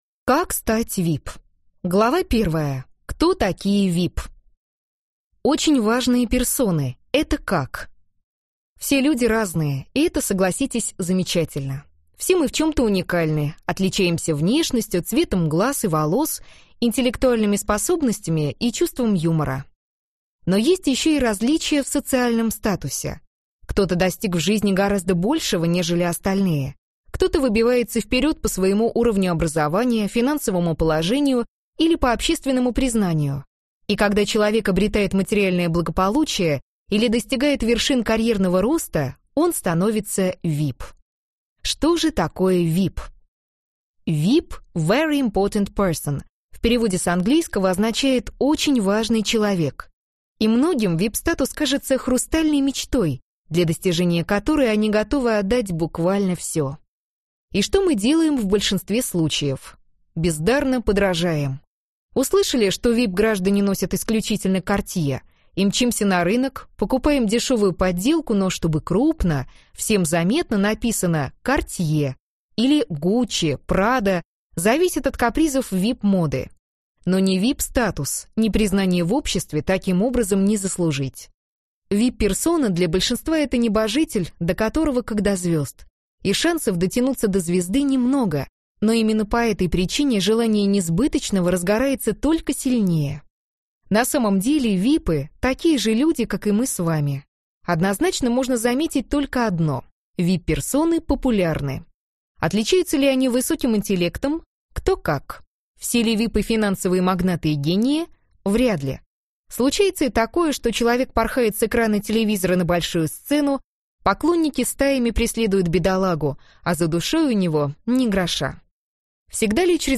Аудиокнига Как стать VIP | Библиотека аудиокниг